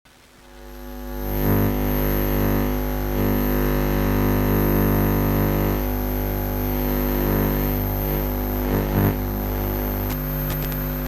Free SFX sound effect: Lightsaber Energy Hum.
Lightsaber Energy Hum
yt_K5xGiVL4BJk_lightsaber_energy_hum.mp3